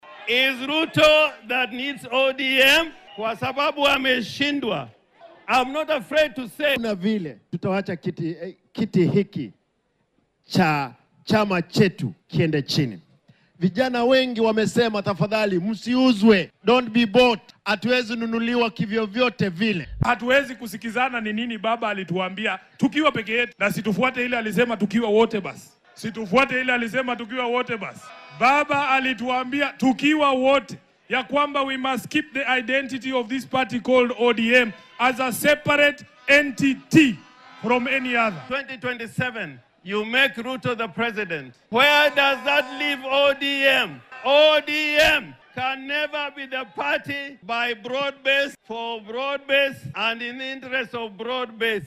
Guddoomiyaha Siaya James Orengo ayaa ku eedeeyay qayb ka mid ah xubnaha xisbiga (ODM) inay la saaxiibeen maamulka Madaxweyne William Ruto iyagoo ku hoos jira heshiiska ballaaran, isagoo ku tilmaamay tallaabo ‘fuleynimo’ ah. Isagoo ka hadlayay shirkii dhalinyarada ee xisbiga ee ka dhacay Mombasa, Orengo wuxuu xusay in Madaxweyne Ruto uu u baahan yahay ODM si uu ugu guulaysto doorashada guud ee sanadka 2027-ka maadaama uu ku guuldaraystay inuu soo jiito xisbiyada mucaaradka ah. Wuxuu tilmaamay in ODM ay tahay inay sii ahaato xisbi kaligiis taagan.